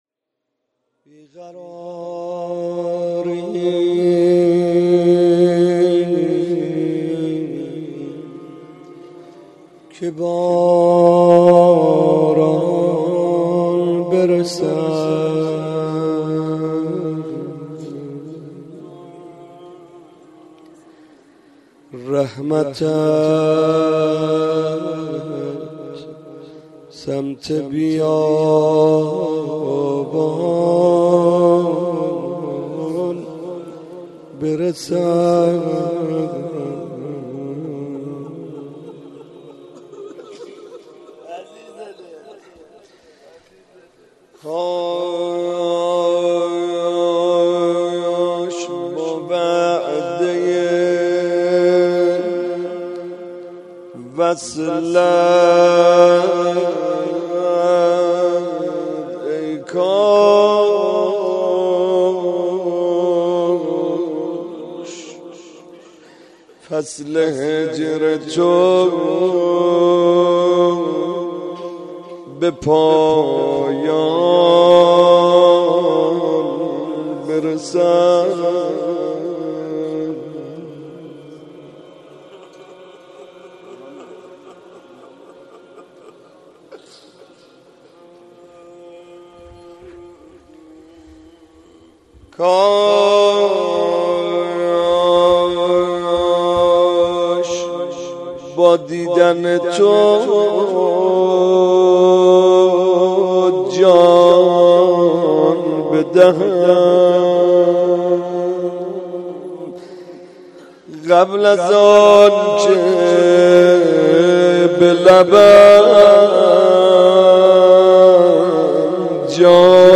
مناجات
روضه